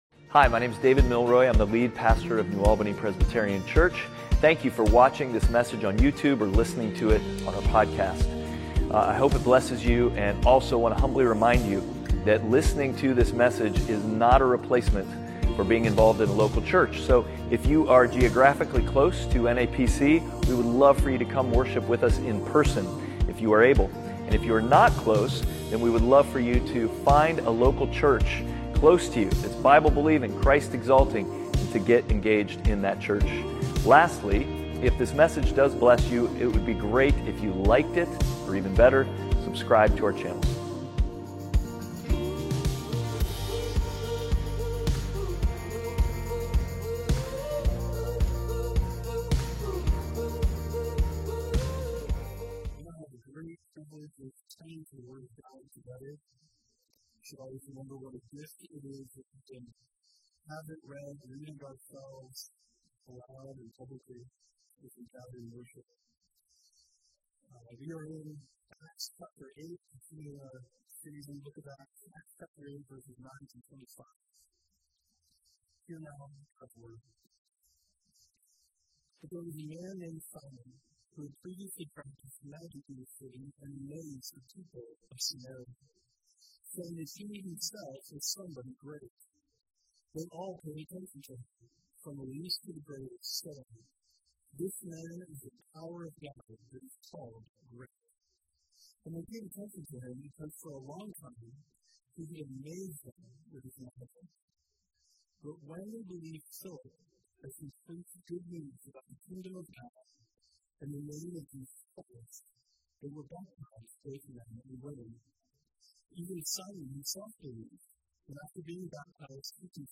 Outward Passage: Acts 8:9-25 Service Type: Sunday Worship « Outward